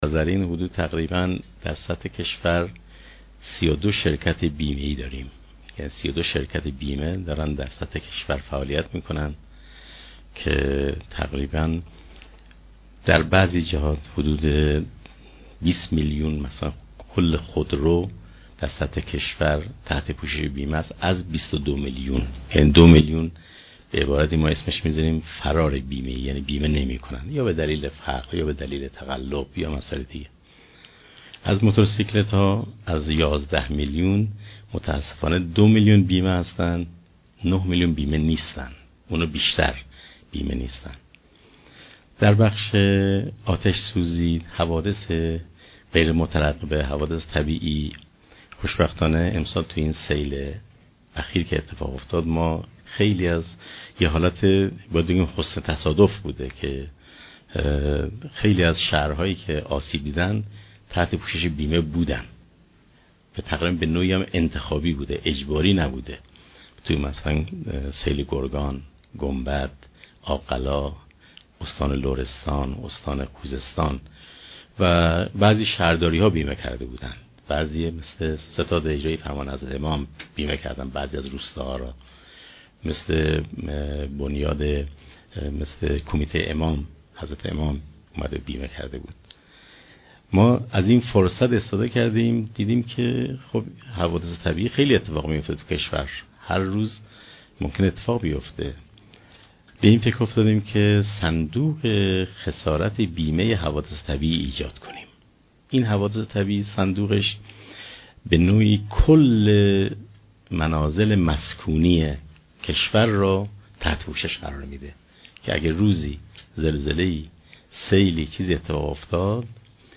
به گزارش خبرنگار سیاسی خبرگزاری رسا، غلامرضا سلیمانی رییس کل بیمه مرکزی امروز در دیدار حضرت آیت الله نوری همدانی با بیان این که 32 شرکت بیمه در کشور فعالیت می کنند، گفت: میلیون ها نفر تحت پوشش بیمه قرار دارند، به عنوان مثال 20 میلیون خورو از 22 میلیون خودرو تحت پوشش بیمه هستند و 2 میلیون نیز به دلایل مختلف فرار بیمه ای دارند.